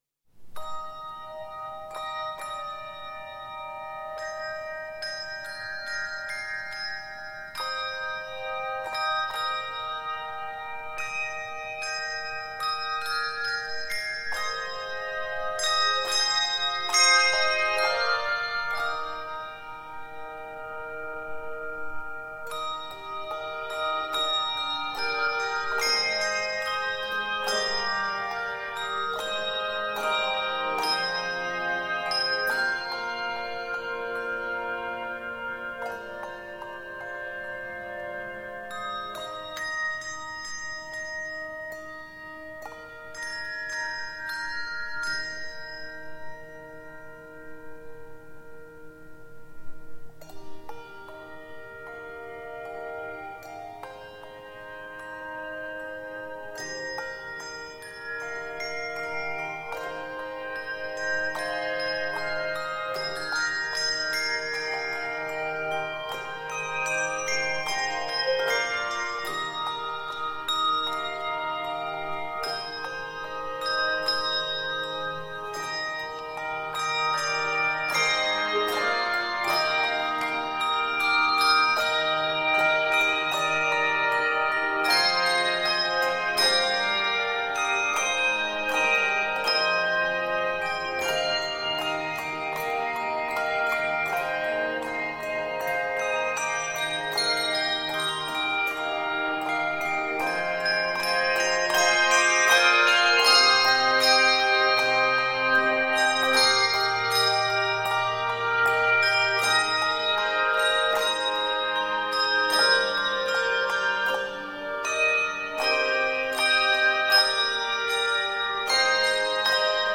This tender rendering of the well-known spiritual